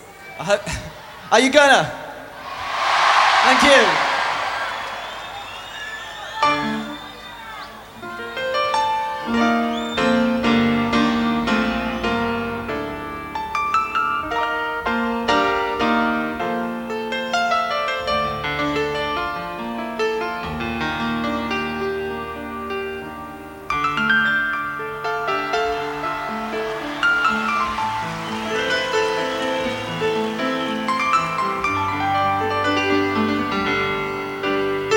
Rock Pop
Жанр: Поп музыка / Рок